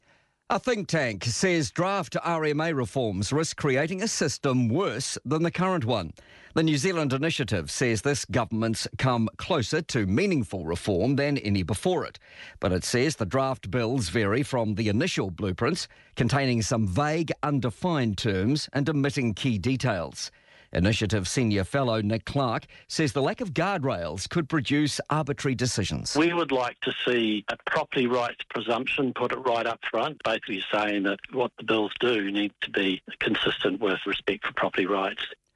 news segment